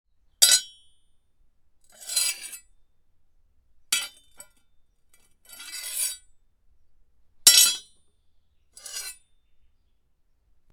Download Free Knife Sound Effects | Gfx Sounds
Knife-or-dagger-sharpening-metal-blade-2.mp3